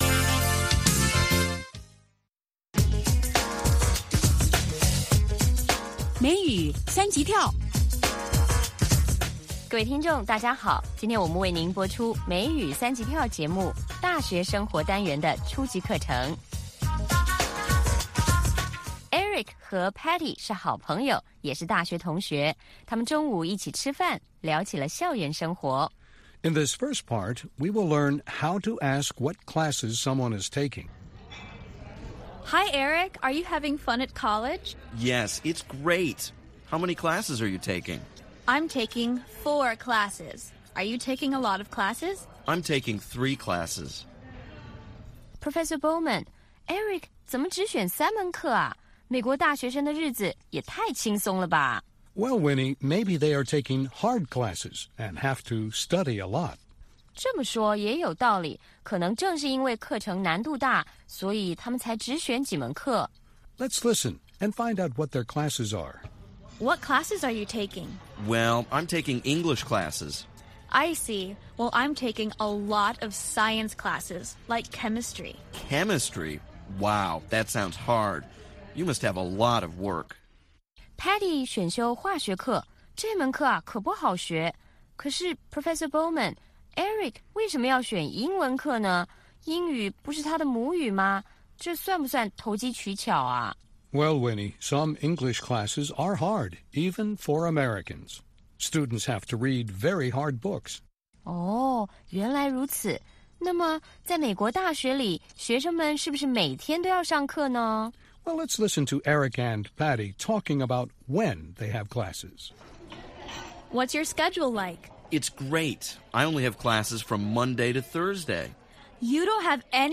北京时间下午5-6点广播节目。广播内容包括收听英语以及《时事大家谈》(重播)